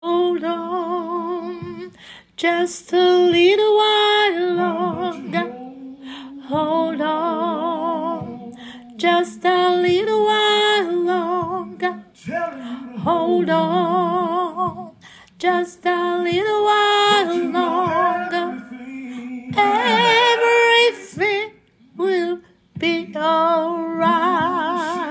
Sop
Hold-on-SOPRANO.mp3